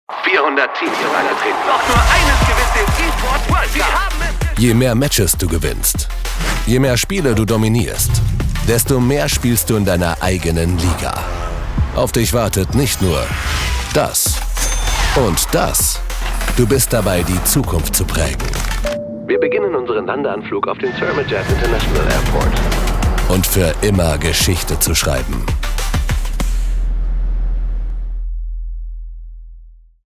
Werbung TV - Meggle